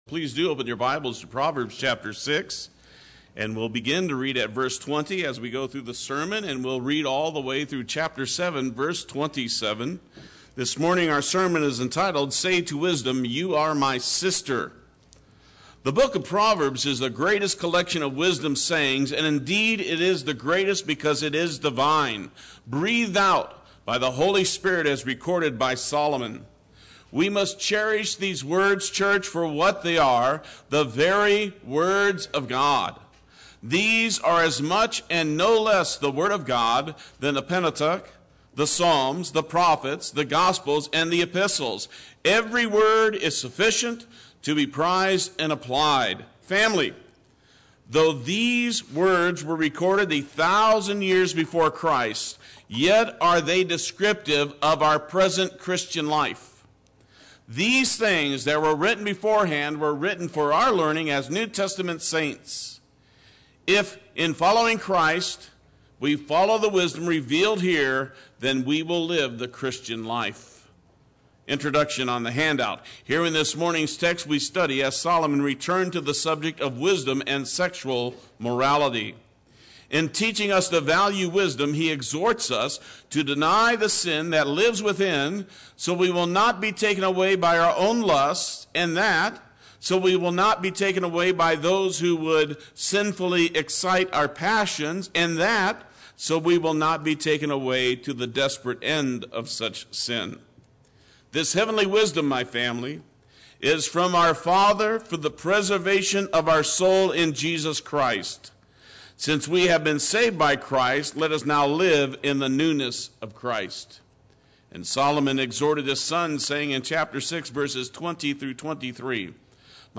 Play Sermon Get HCF Teaching Automatically.
You are My Sister Sunday Worship